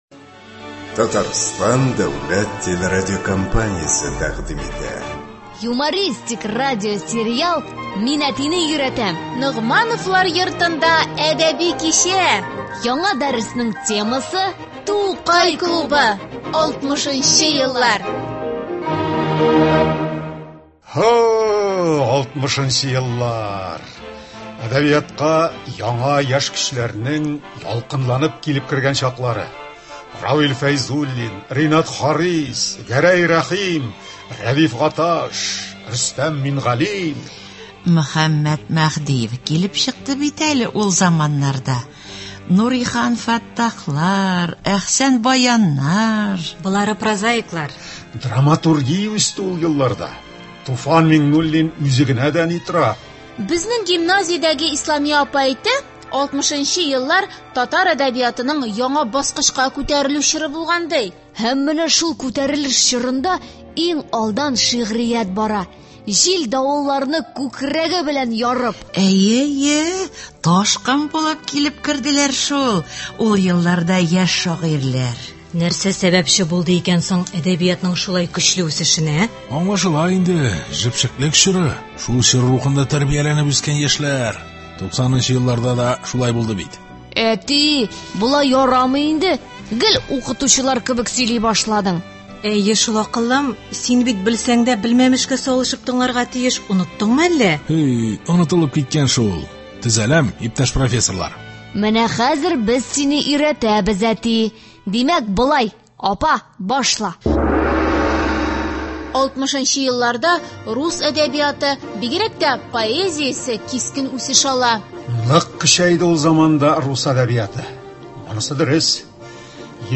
Радиосериал.